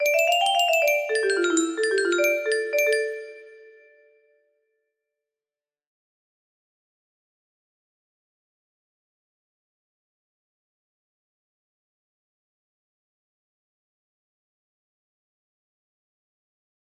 Giulio Cesare music box melody